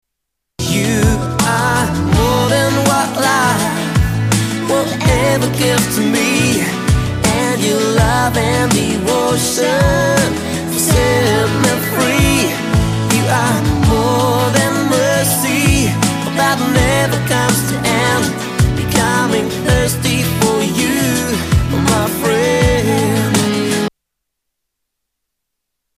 STYLE: Pop
Poppy Eurodance/electronica indie worship.